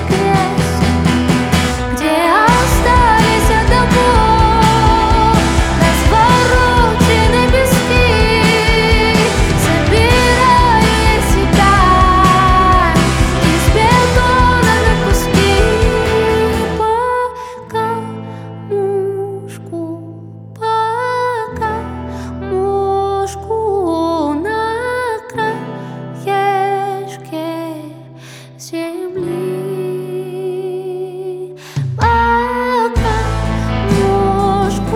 Indie Rock Alternative Rock